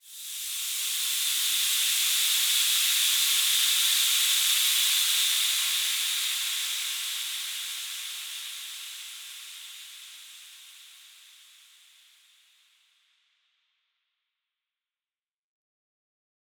Index of /musicradar/shimmer-and-sparkle-samples/Filtered Noise Hits
SaS_NoiseFilterD-04.wav